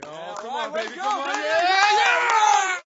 Crowd01-rolling.wav